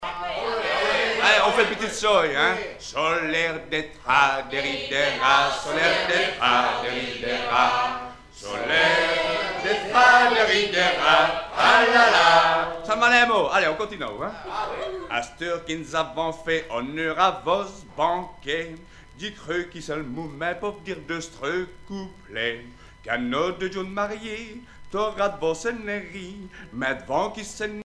Chanson de banquet
circonstance : fiançaille, noce
Pièce musicale éditée